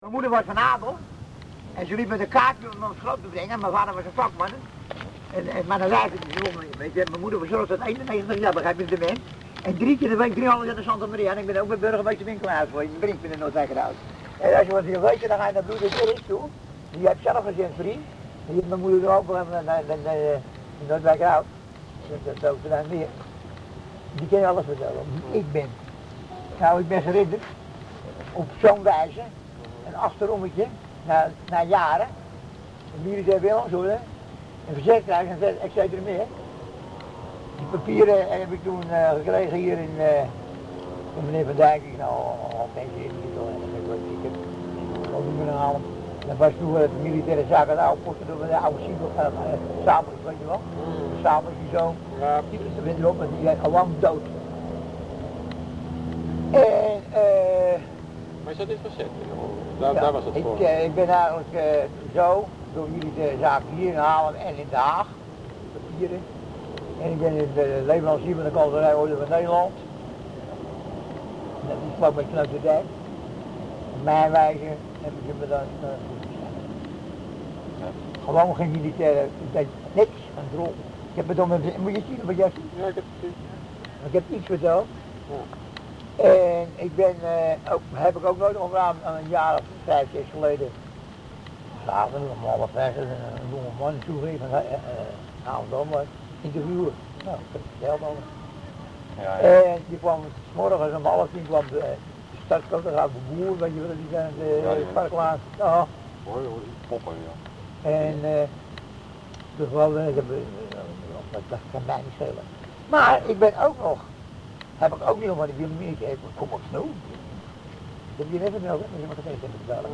Originele opname van het gehele interview dat aan het schrijven van bovenstaande monoloog voorafging (.mp3, 14.543 Kb).